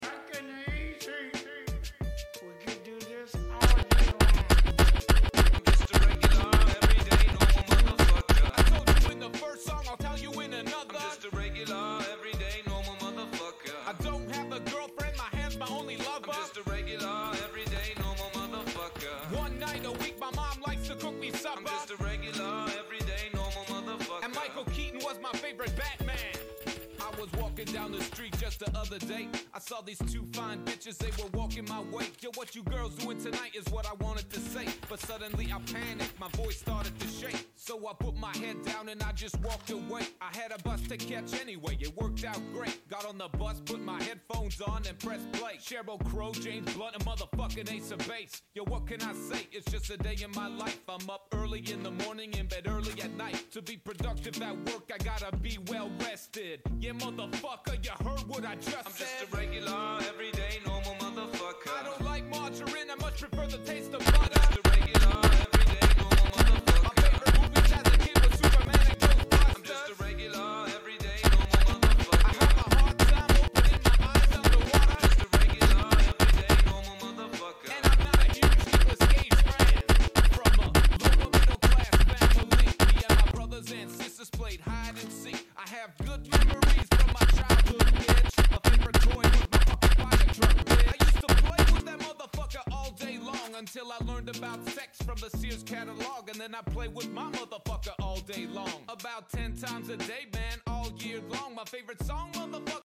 Review AS Val Automatic Rifle Sound Effects Free Download